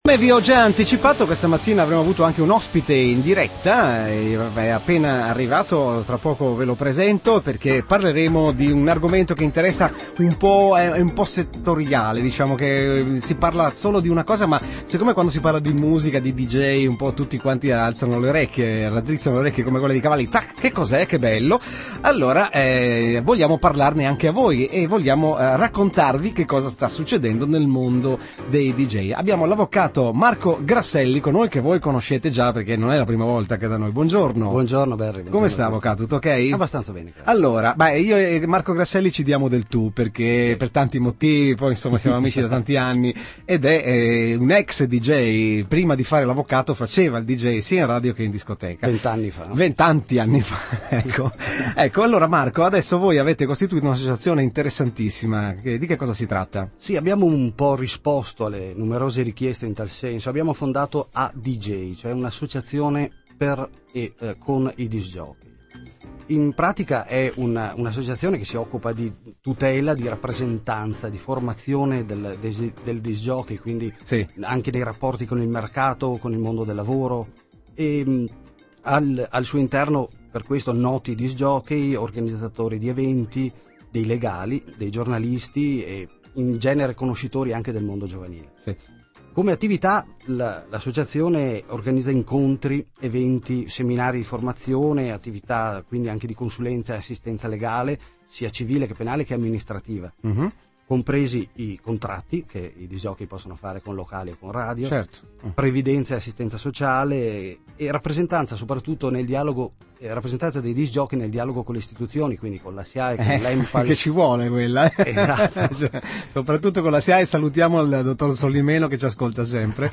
Intervista avv.